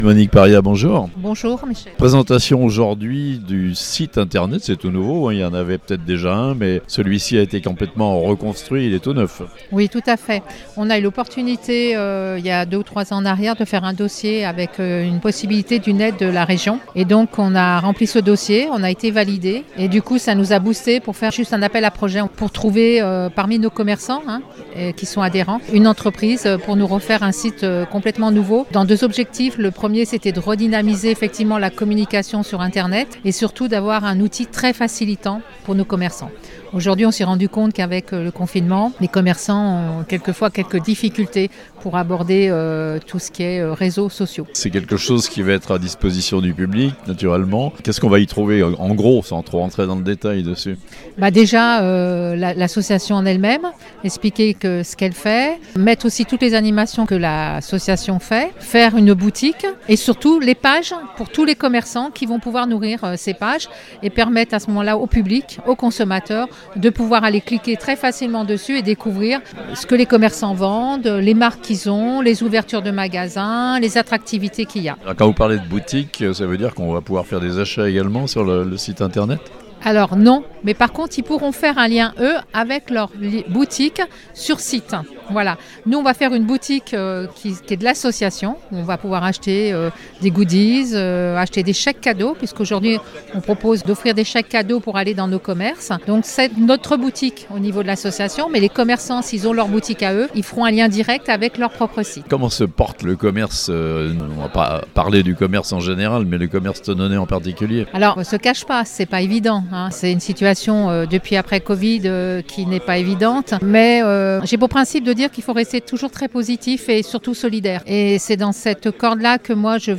L'association "Thonon Commerces et Entreprises" a présenté son nouveau site internet (interview)